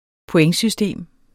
Udtale [ poˈεŋ- ]